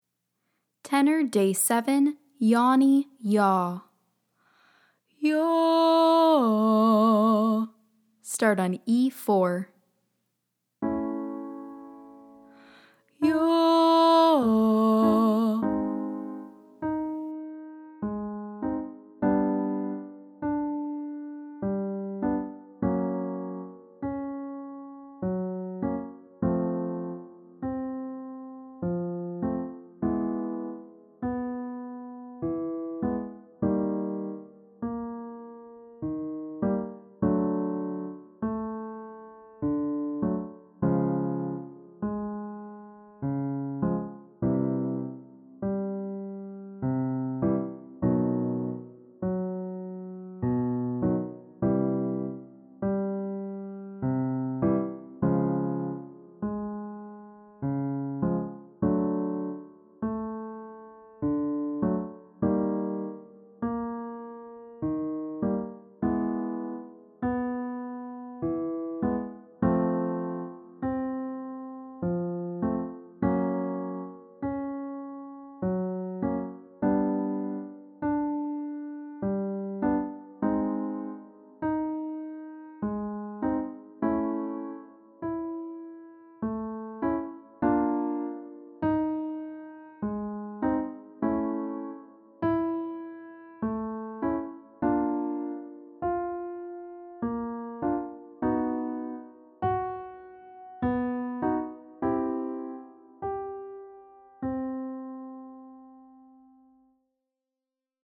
Day 7 - Tenor - Yawny YAH